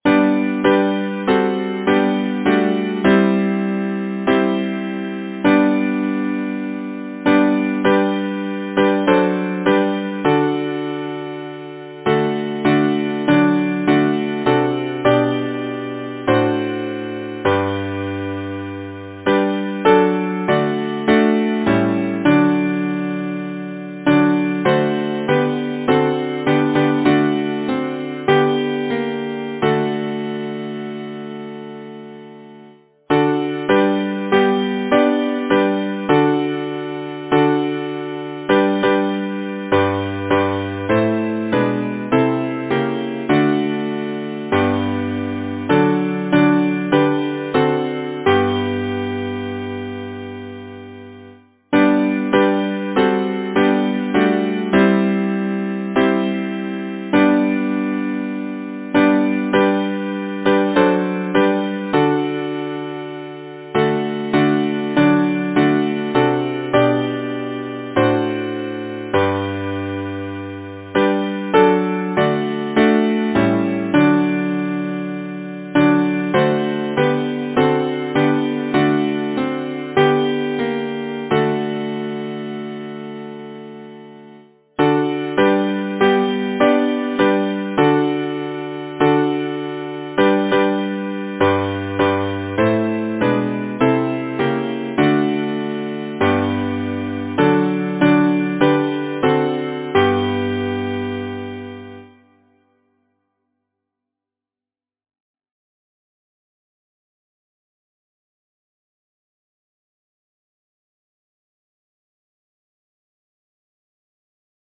Title: The Vesper Bell Composer: John Sewell Lyricist: G. T. Newsumcreate page Number of voices: 4vv Voicing: SATB Genre: Secular, Partsong
Language: English Instruments: A cappella